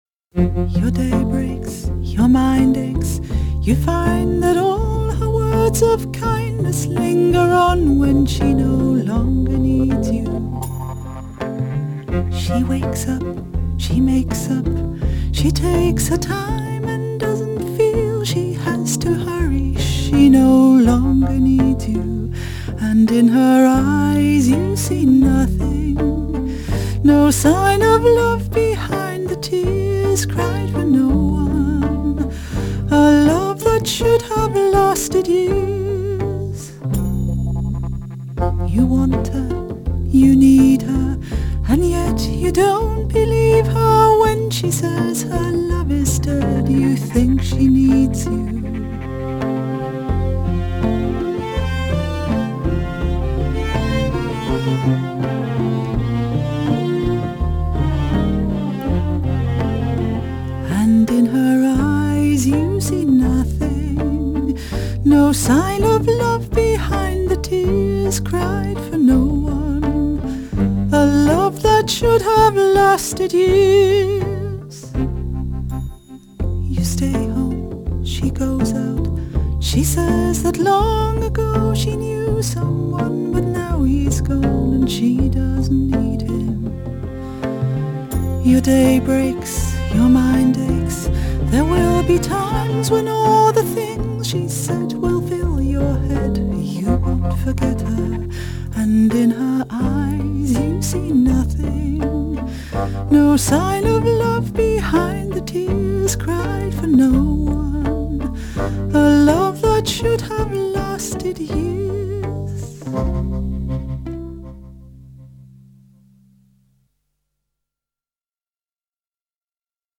Genre: Sex Music.